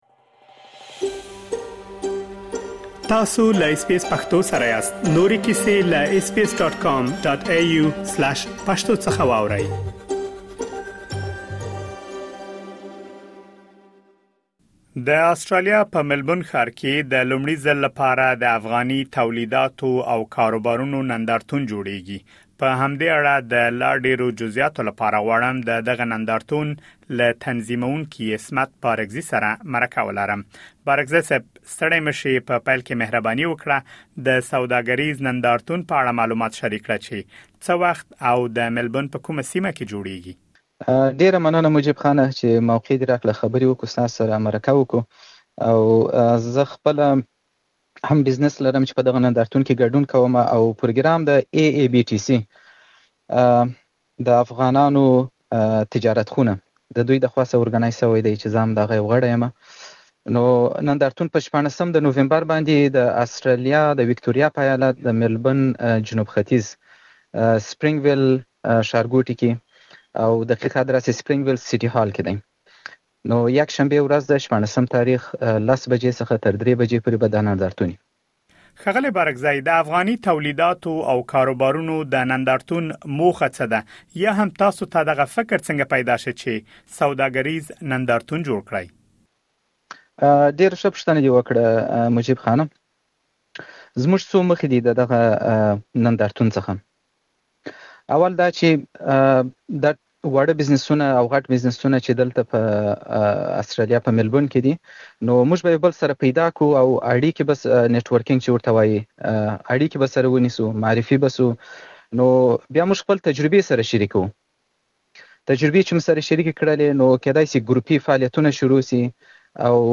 مهرباني وکړئ لا ډېر معلومات په ترسره شوې مرکې کې واورئ.